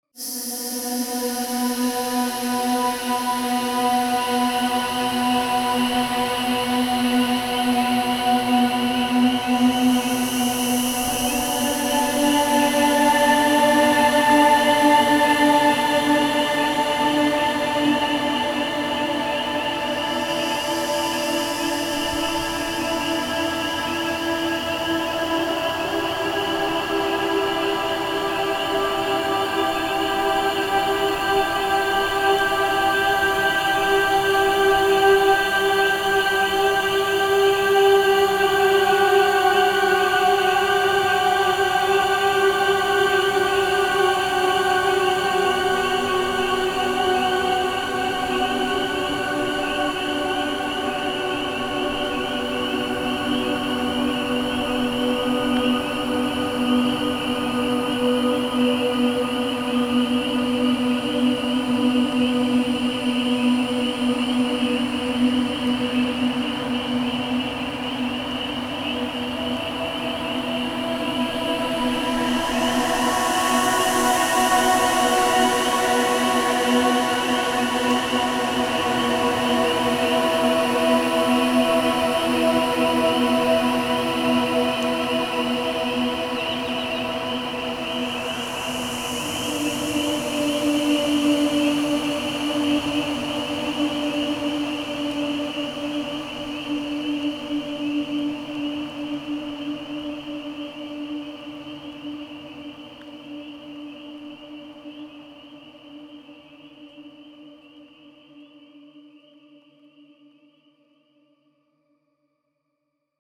Home > Music > Ambient > Nature > Smooth > Mysterious